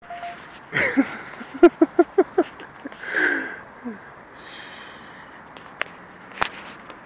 Laugh